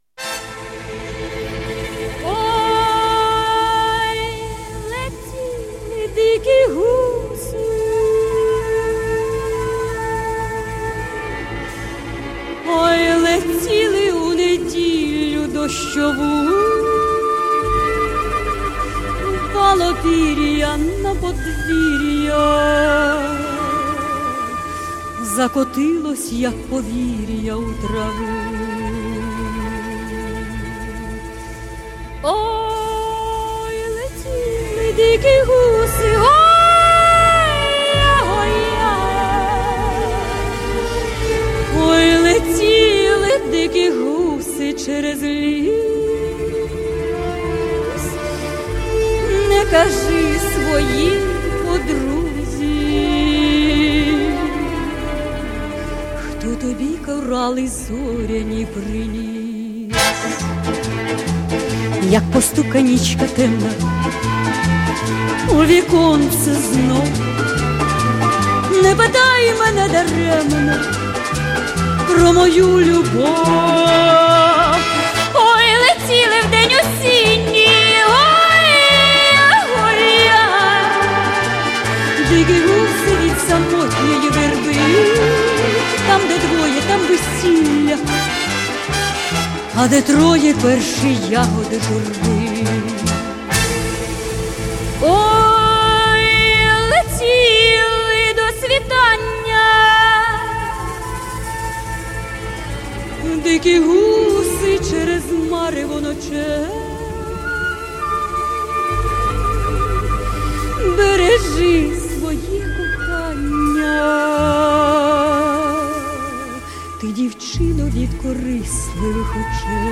на украинском в исполнении